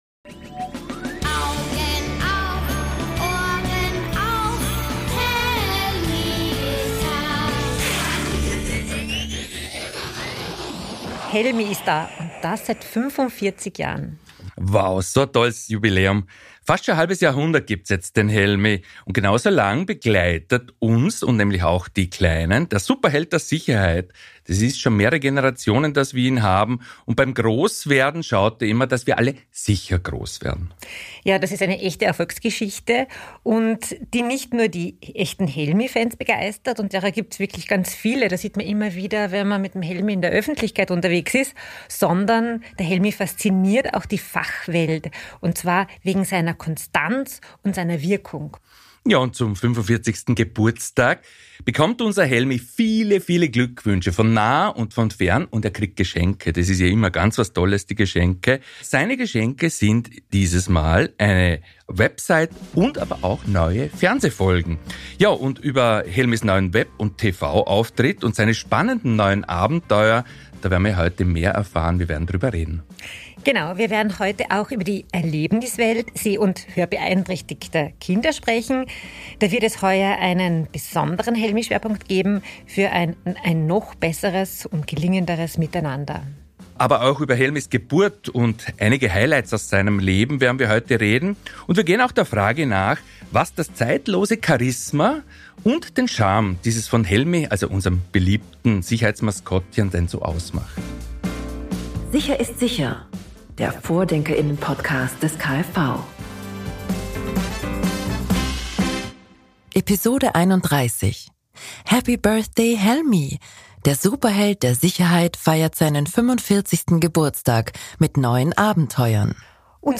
Und ein zusätzlicher Überraschungsgast mischt das Studio dann auch noch auf.